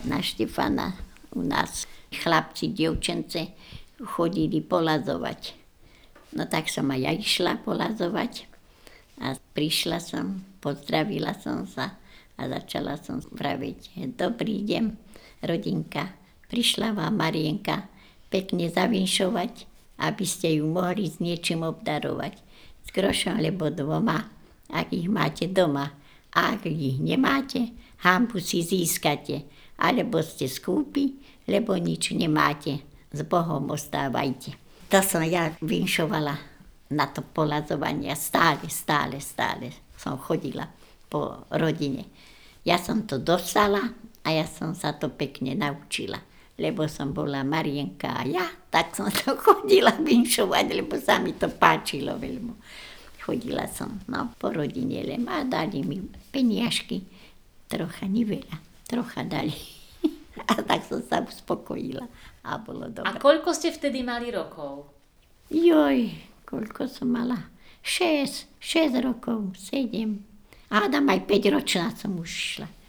Miesto záznamu Ľuboreč
vinš